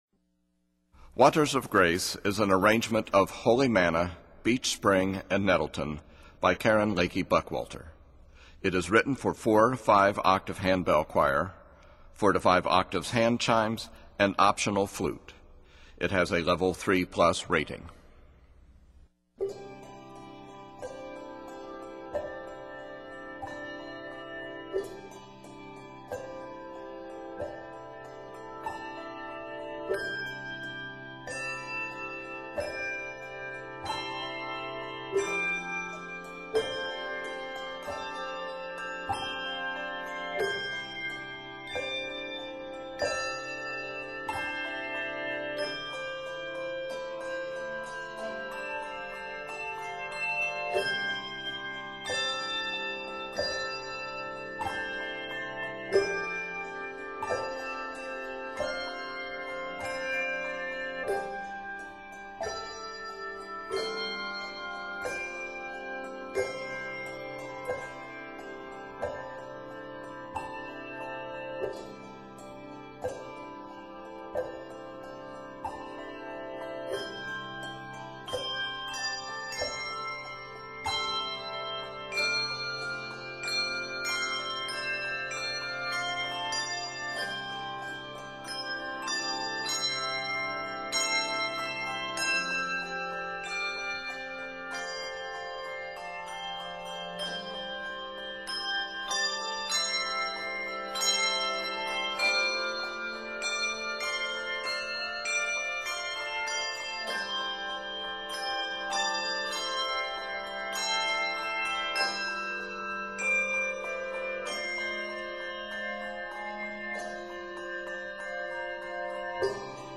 Octaves: 4-5